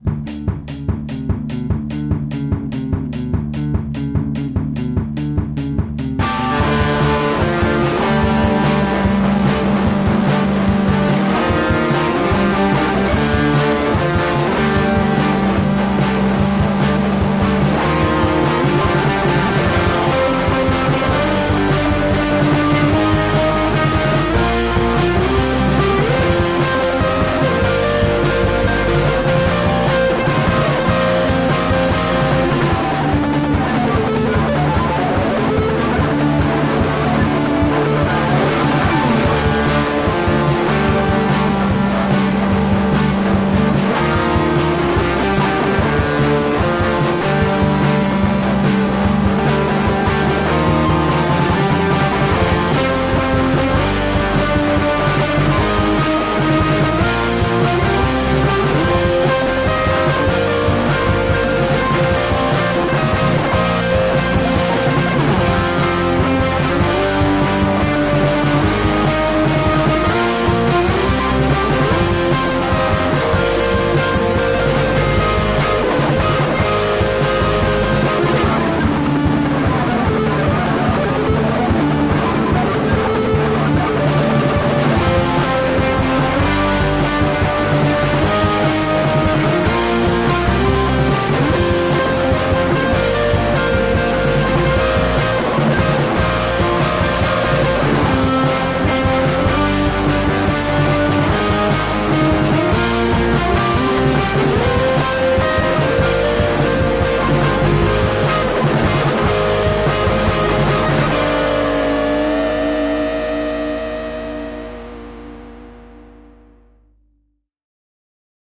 Home > Music > Rock > Bright > Running > Chasing